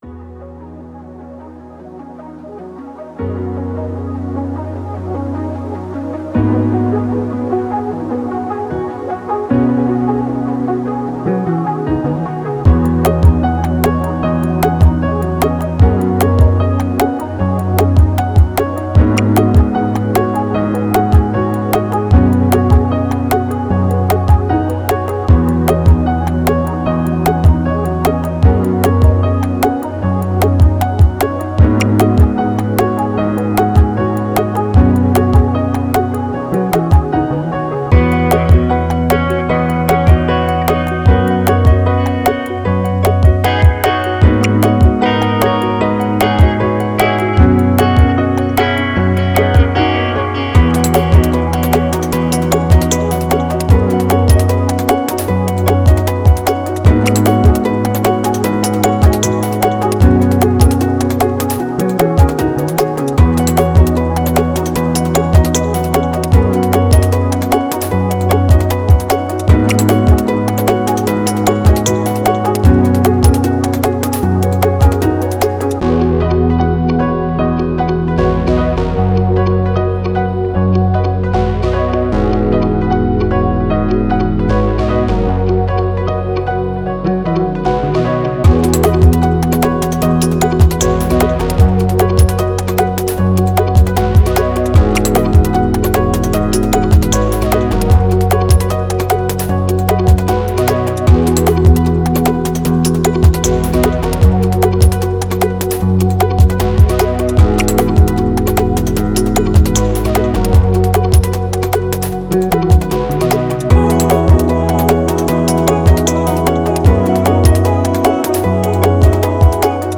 Electronic, Ambient, Thoughtful, Melancholic